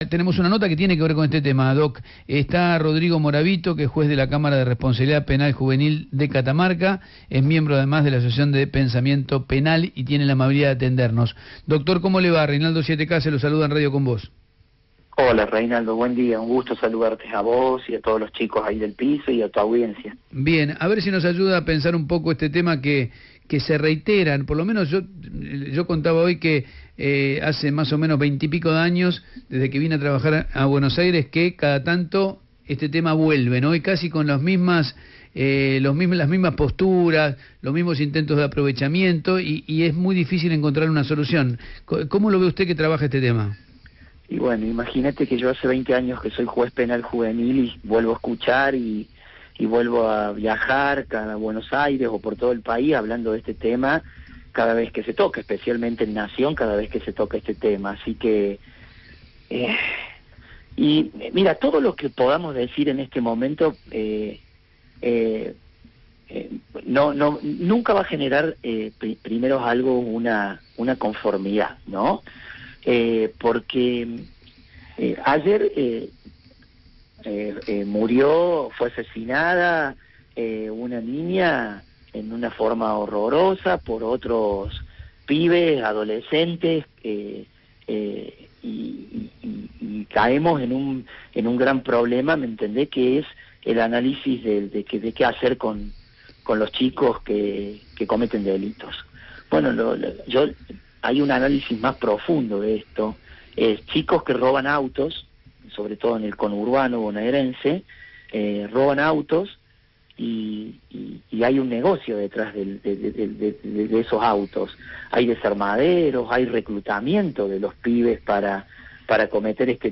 Entrevista a Morabito.mp3